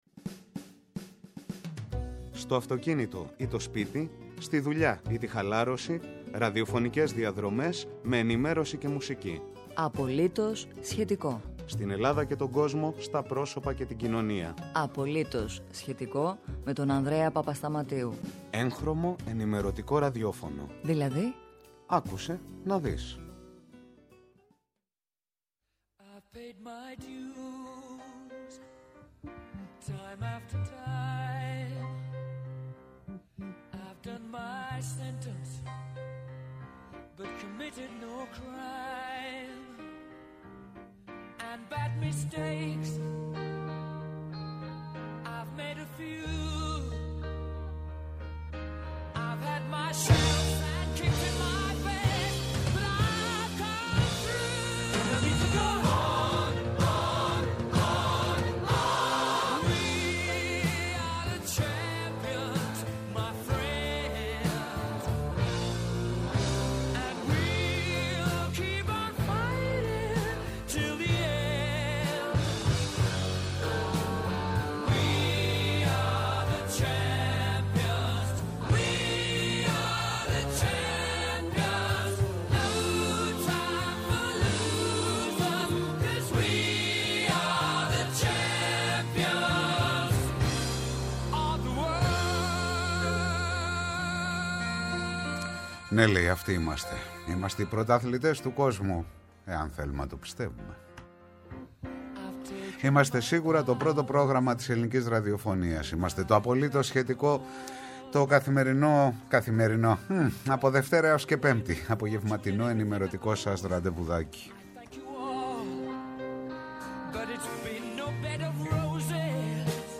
Απογευματινή ενημέρωση και ραδιόφωνο; «Απολύτως … σχετικό»!